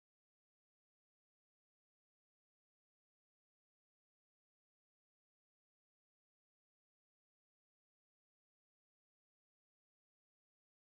02. Tropár 3. hang Szent Lukács apostol.wma